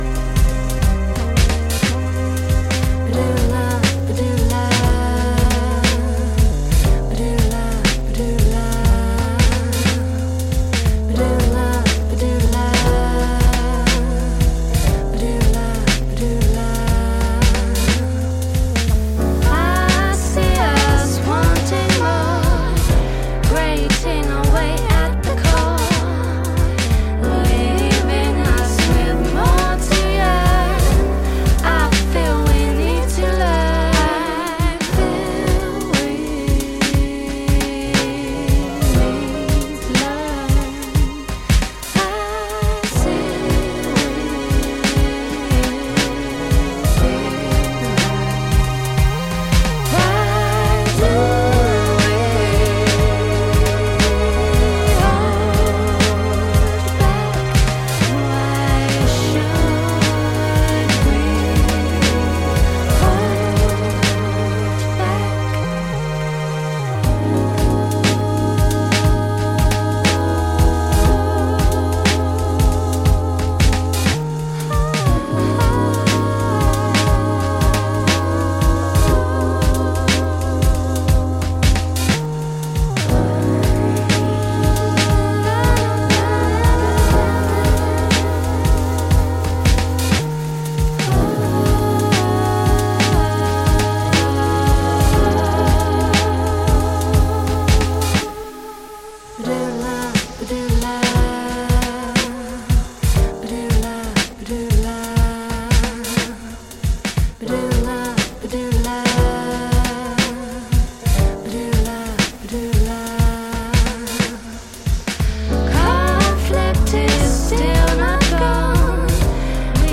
ジャンル(スタイル) HOUSE / BROKEN BEAT / NU JAZZ / NU SOUL